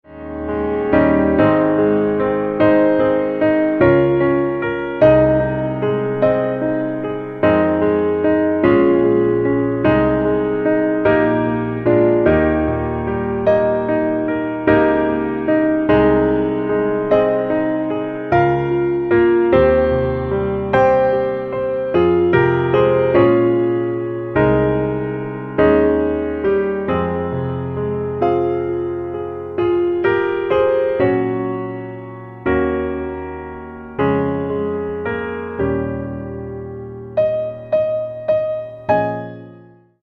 Piano - Low